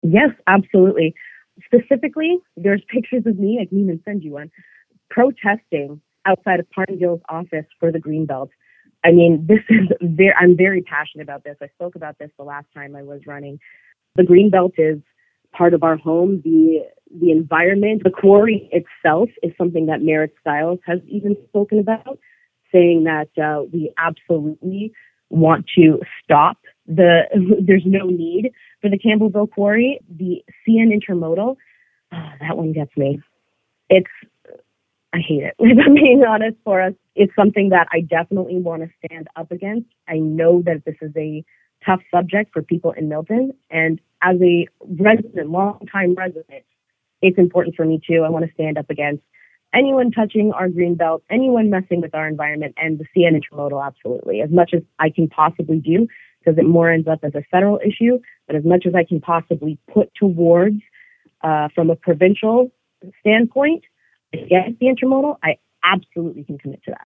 Here’s our interview: